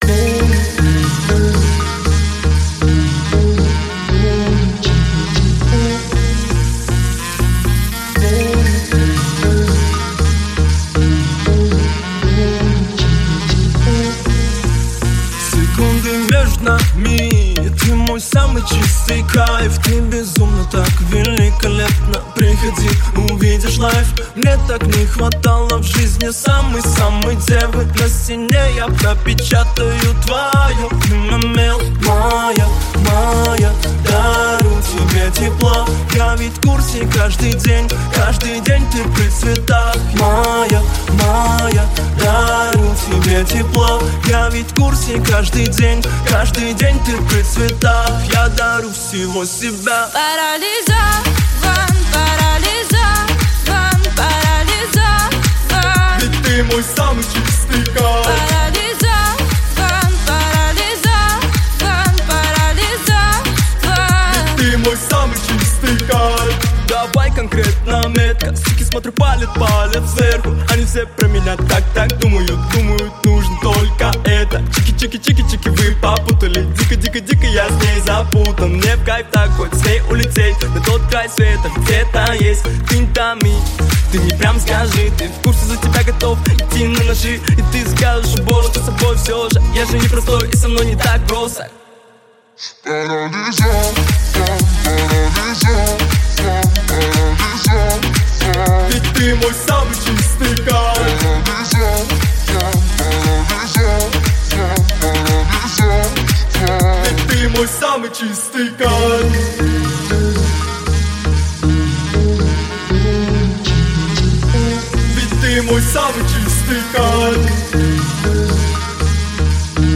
это трек в жанре хип-хоп, исполненный дуэтом